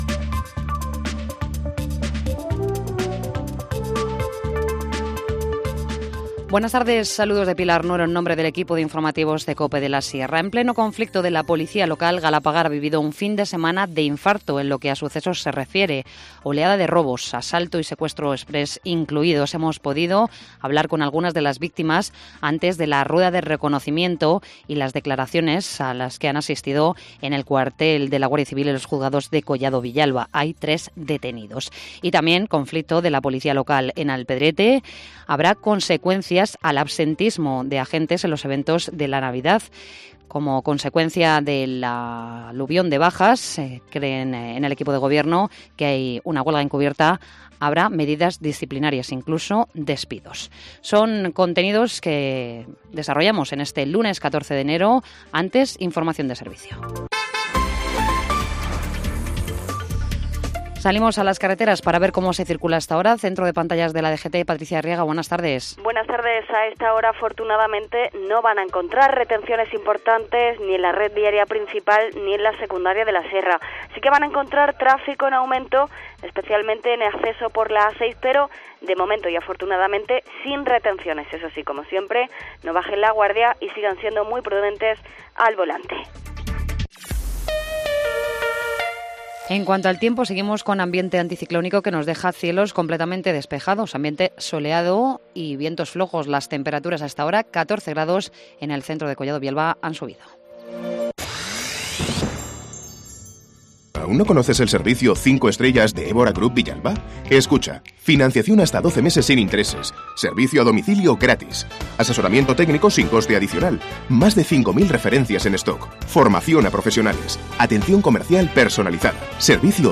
Informativo Mediodía 14 enero- 14:20h